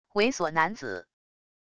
猥琐男子wav音频